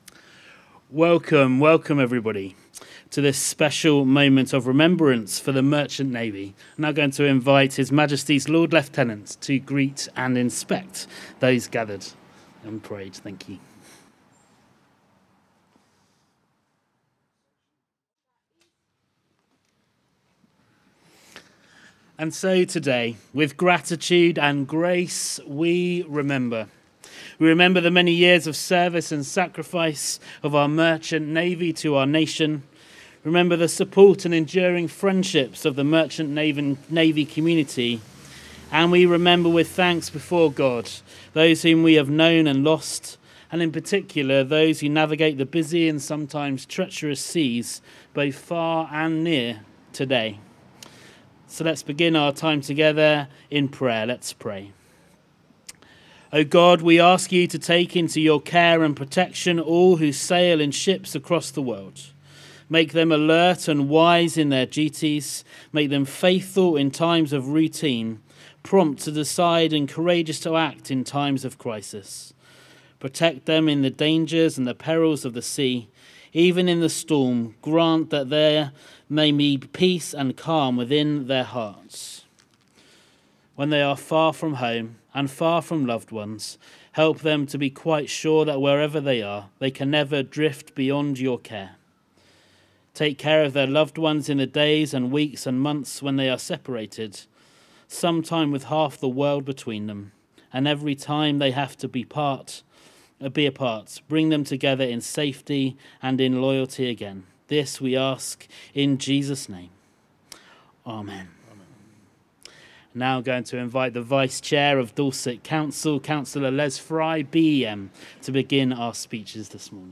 The annual commemoration to honour our Merchant Navy took place at the Memorial area outside County Hall in Dorchester on 3rd September with a flag raising ceremony.
KeeP 106 provided the sound services for the occasion and you can hear a recording of the ceremony below followed by interviews with Cllr Les Fry and the retiring Lord-Lieutenant
Merchant-Navy-Day-3rd-Sept-2024-County-Hall-Dorchester.mp3